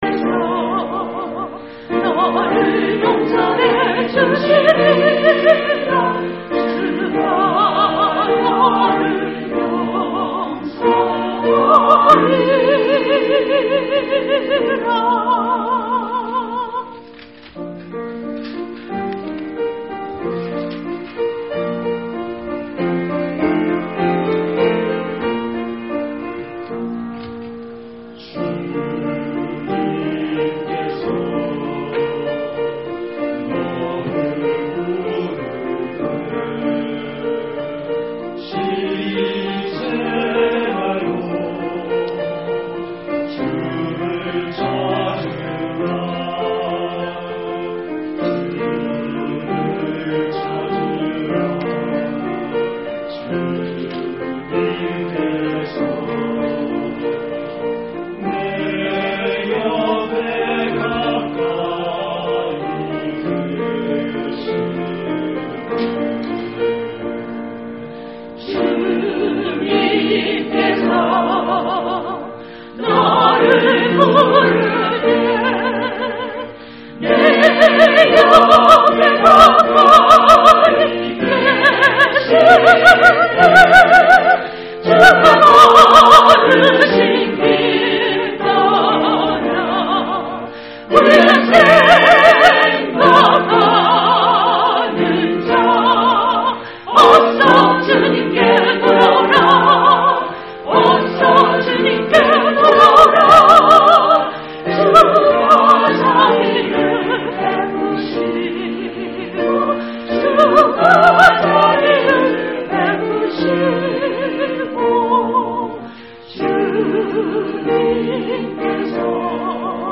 choir song